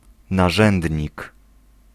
Ääntäminen
US : IPA : [ˌɪn.stɹə.ˈmɛn.təl]